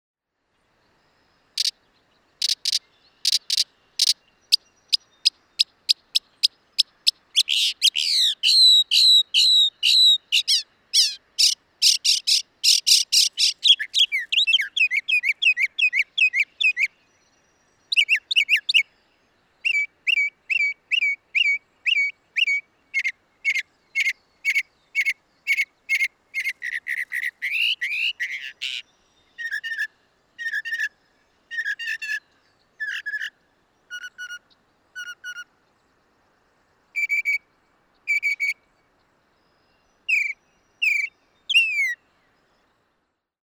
Звуки пересмешника
Пение пернатой птицы